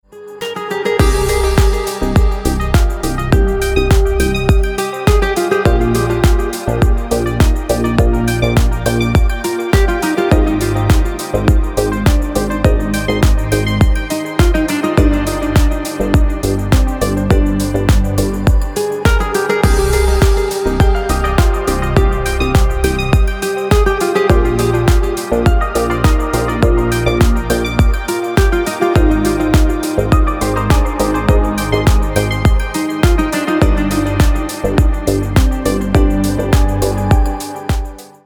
Deep House рингтоны